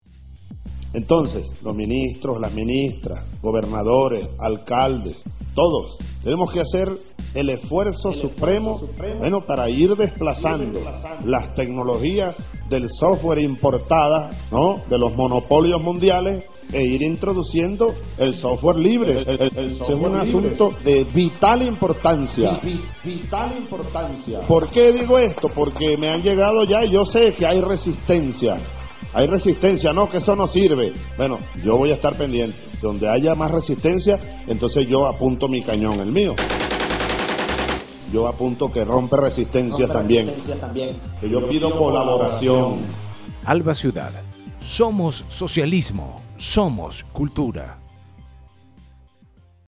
Micro sobre el entonces Presidente Hugo Chávez y el Software Libre (2011)